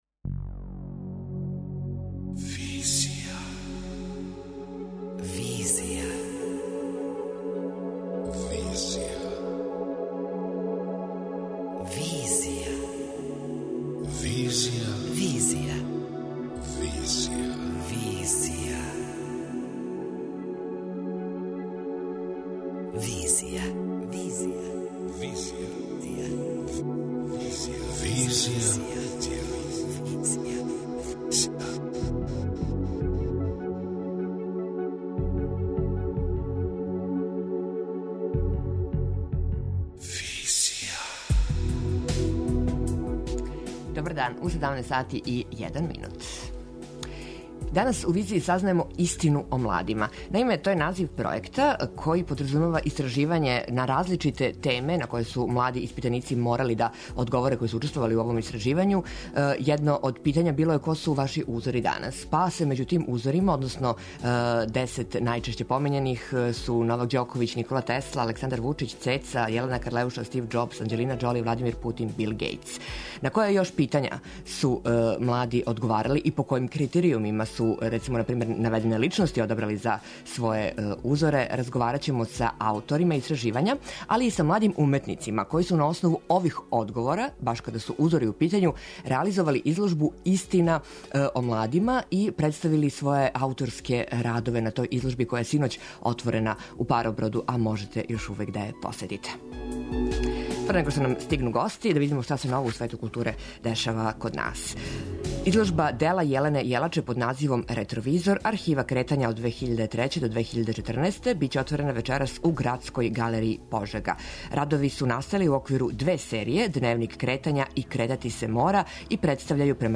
преузми : 26.87 MB Визија Autor: Београд 202 Социо-културолошки магазин, који прати савремене друштвене феномене.